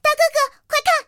T-127获得资源语音.OGG